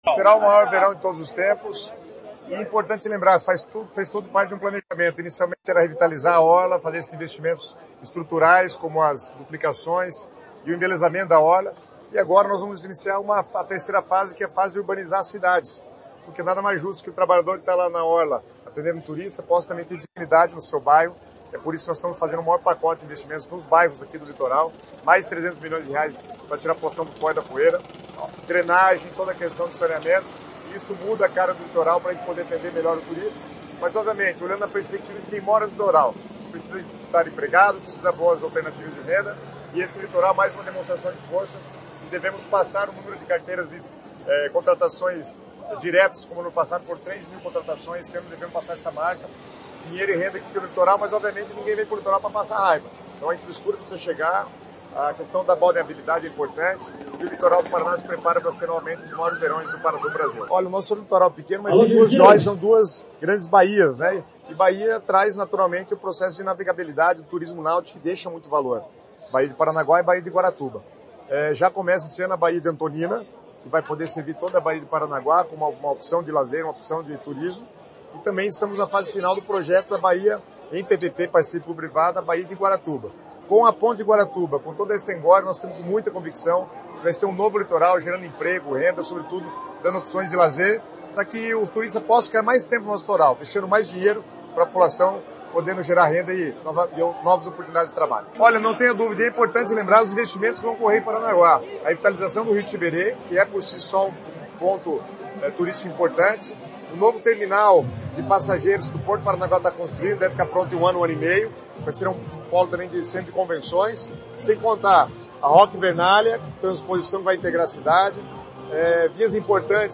Sonora do secretário das Cidades, Guto Silva, sobre o lançamento do Verão Maior Paraná